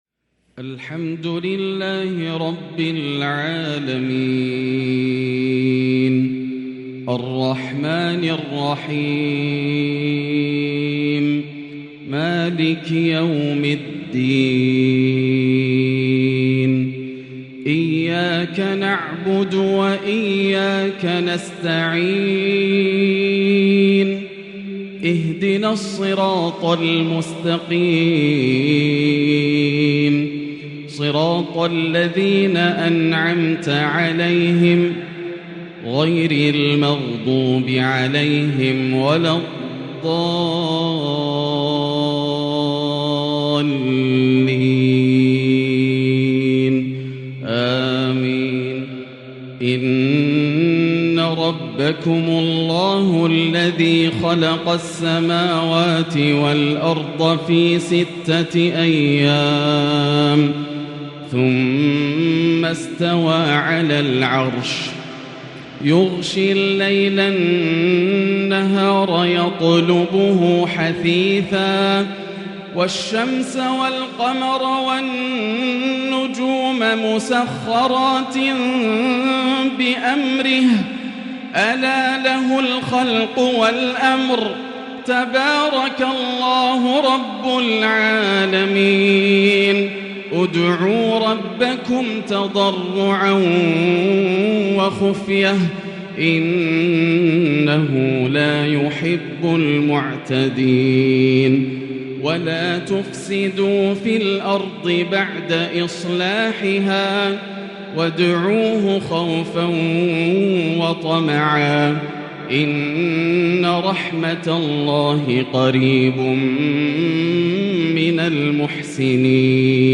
تحابير خيالية وتنوع آسر للشيخ د. ياسر الدوسري من محراب الحرم المكي > مقتطفات من روائع التلاوات > مزامير الفرقان > المزيد - تلاوات الحرمين